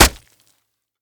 Flesh Chop 2 Sound
horror